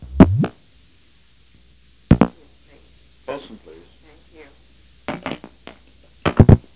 Location: White House Telephone
The President talked with the White House operator.